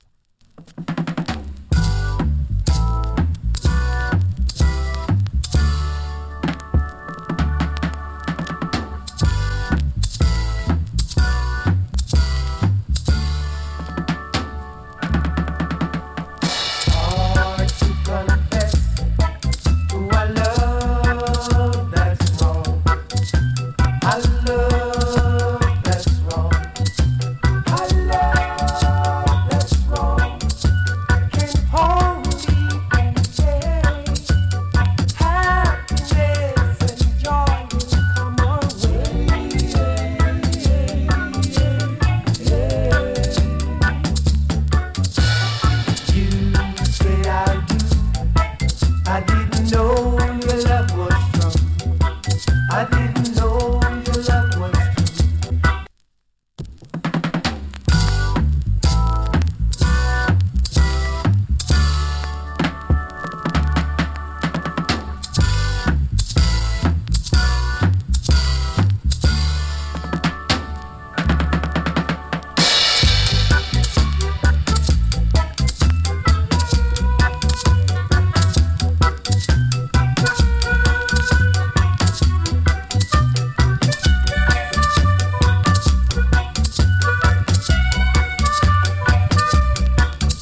チリ、パチノイズ少々有り。